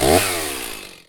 PNChainsword.wav